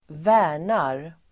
Uttal: [²v'ä:r_nar]